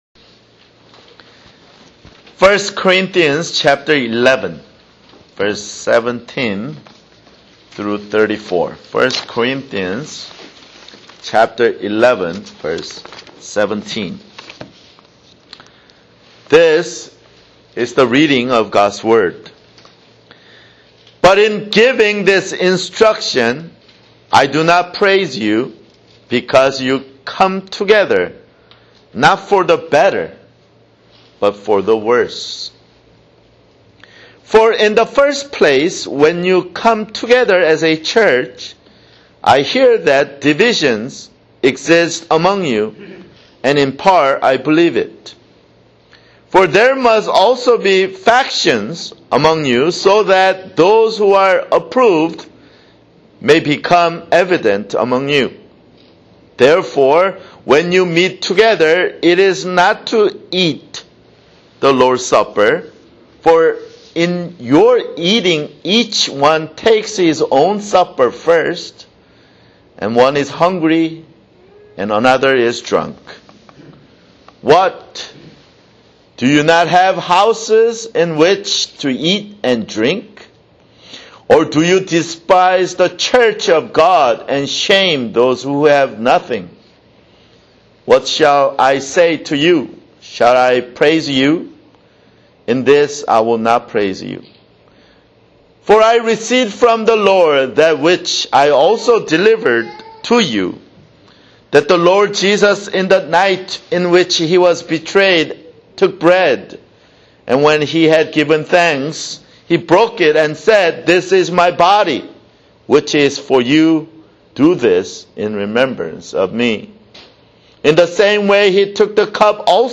[Sermon] 1 Corinthians 11:17-34 1 Corinthians 11:17-34 (Lord's Supper)